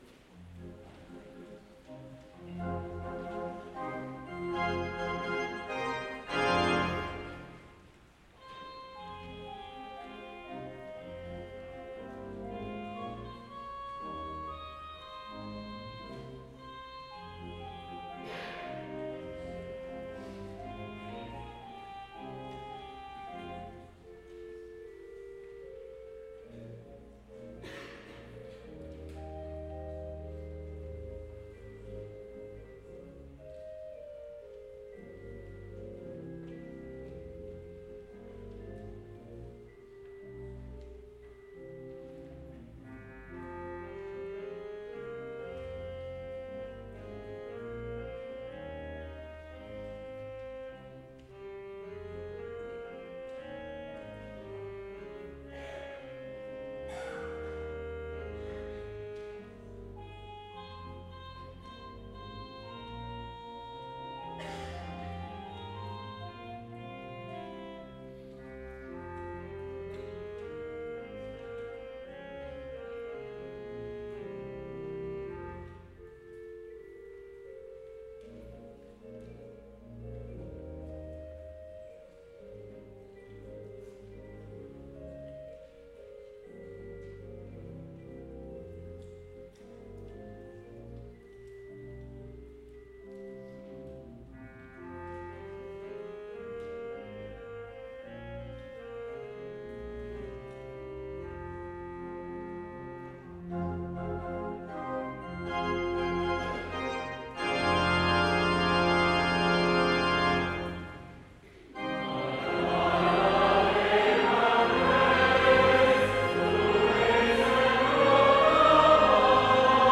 Hymn Festival
The hymn festival music below is selected from recordings from both the 5:30 Saturday and 8:30 Sunday hymn festivals on Saturday 21/22, 2024.